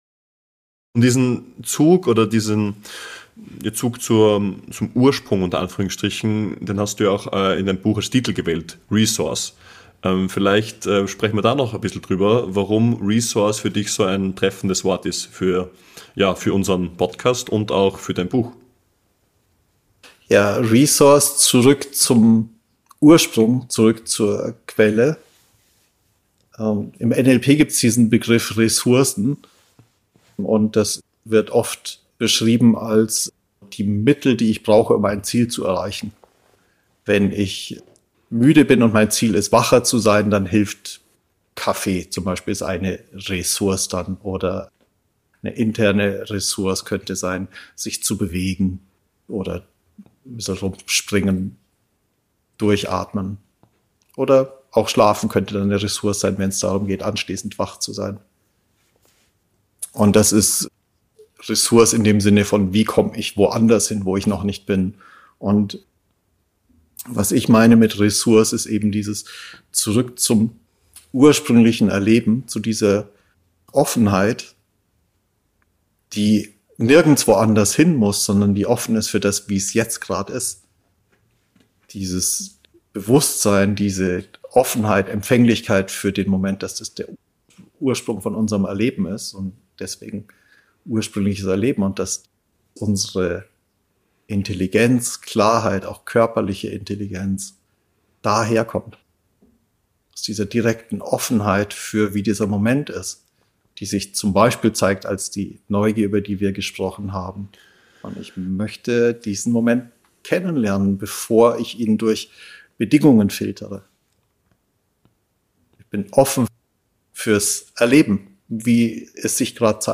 Ein Gespräch über Klarheit ohne Druck, über Wachheit ohne Kontrolle, und über Spielräume, die sich öffnen, wenn Erleben nicht verwaltet, sondern zugelassen wird.